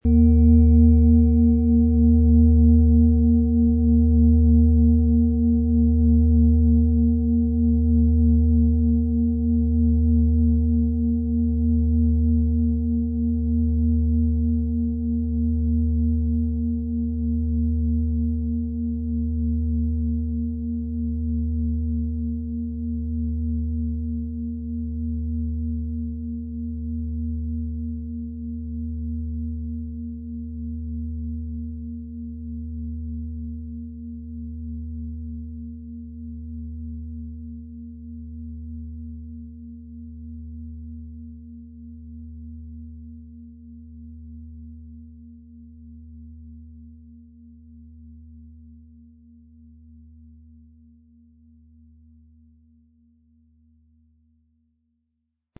• Mittlerer Ton: Mond
• Höchster Ton: Mond
Wie klingt diese tibetische Klangschale mit dem Planetenton Eros?
Spielen Sie die Eros mit dem beigelegten Klöppel sanft an, sie wird es Ihnen mit wohltuenden Klängen danken.
MaterialBronze